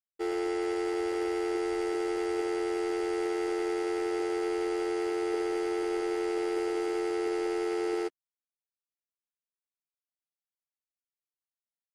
Futzed Dial Tone.